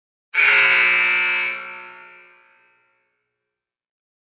SFX – SPORTS BUZZER
SFX-SPORTS-BUZZER.mp3